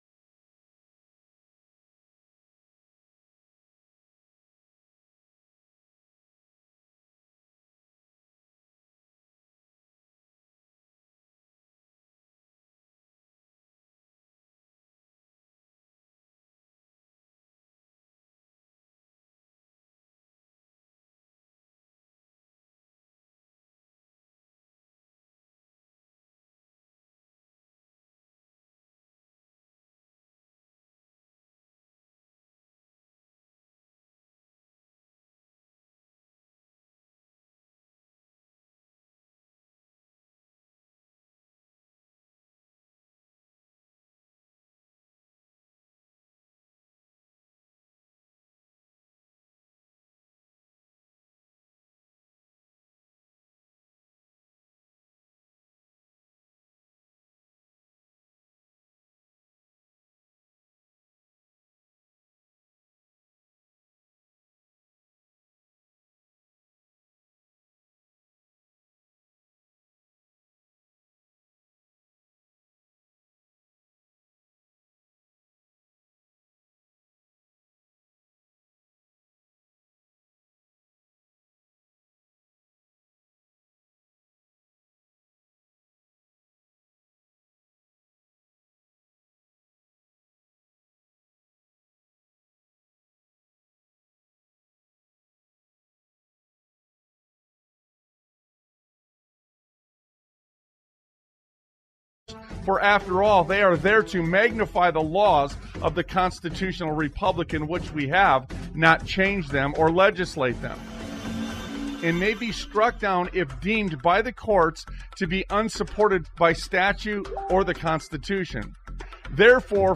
Talk Show Episode, Audio Podcast, Sons of Liberty Radio and Must Be Congruent on , show guests , about Must Be Congruent, categorized as Education,History,Military,News,Politics & Government,Religion,Christianity,Society and Culture,Theory & Conspiracy